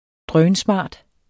Udtale [ ˈdʁœːnˈsmɑˀd ] Betydninger meget smart